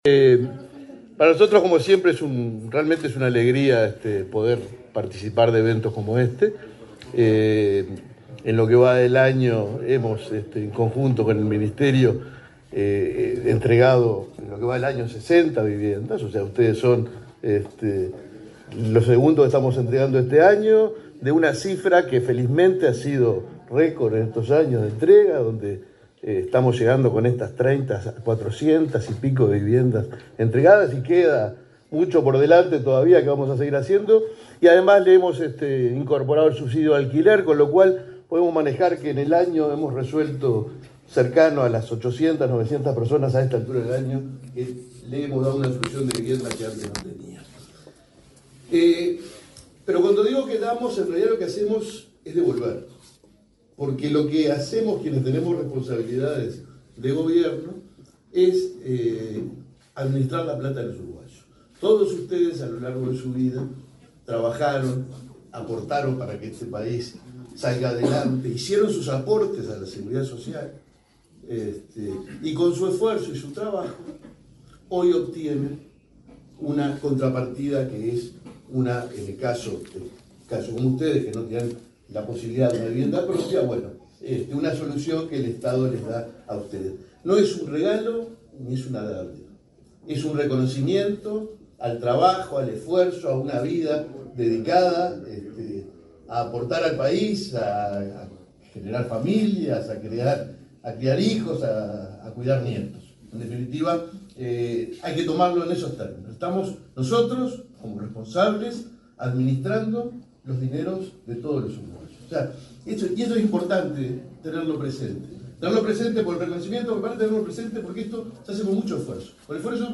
Palabras de autoridades en acto de entrega de viviendas
El presidente del Banco de Previsión Social, Alfredo Cabrera, y el ministro de Vivienda, Raúl Lozano, participaron en el acto de entrega de viviendas